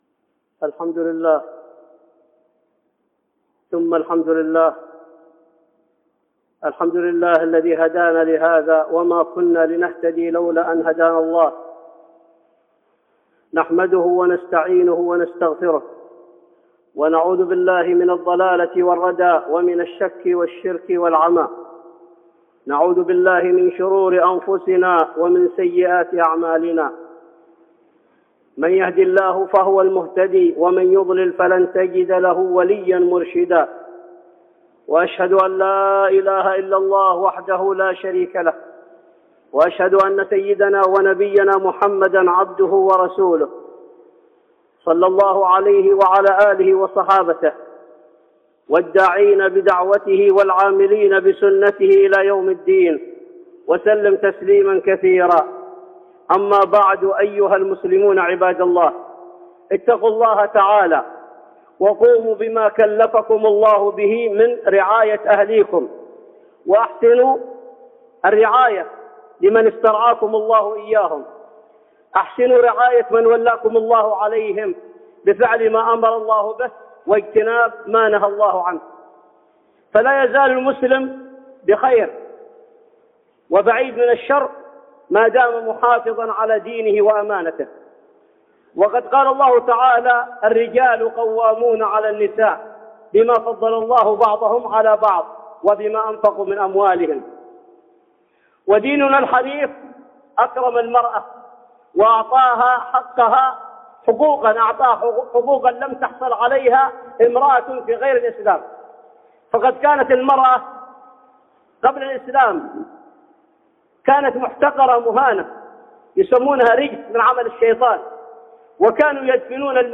خطبة جمعة بعنوان وليس الذكر كالأنثى